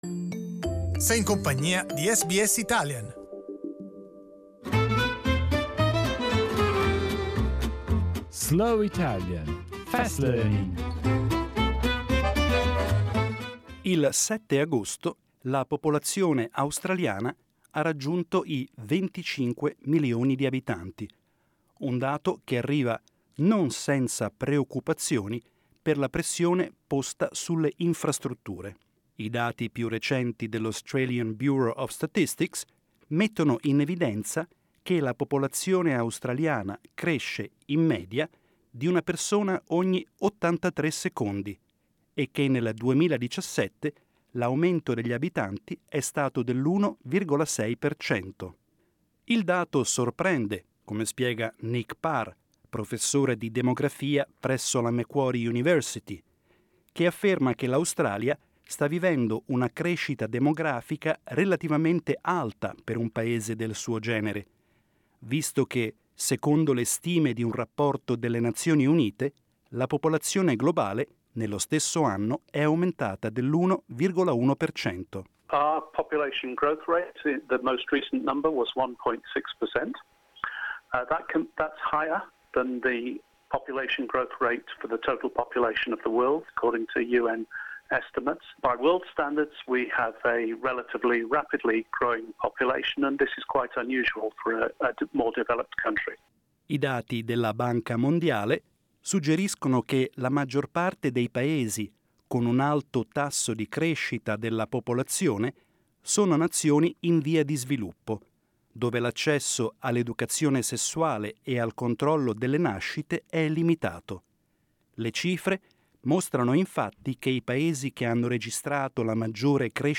SBS Italian news, with a slower pace.